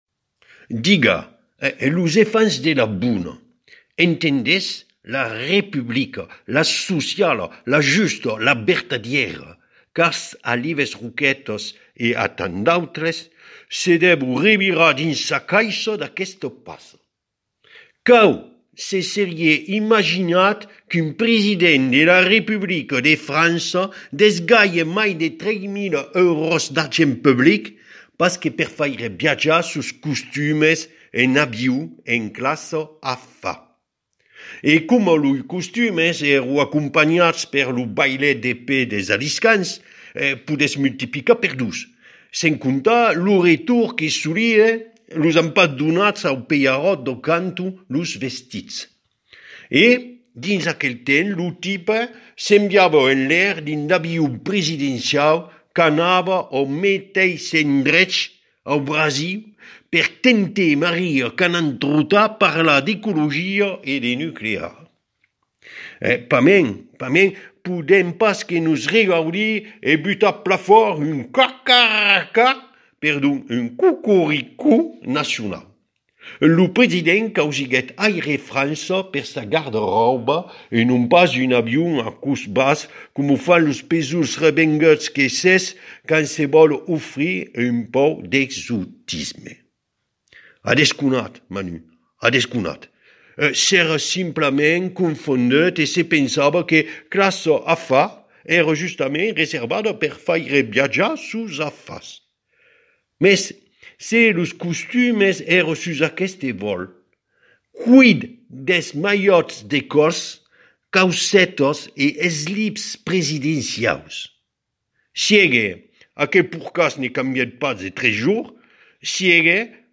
Las guinhadas de l'IEO34 sus ràdio Lengadòc